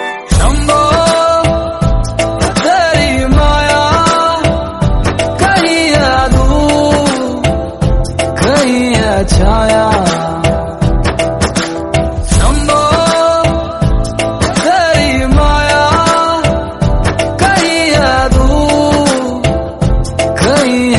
CategoryDevotional / Bhakti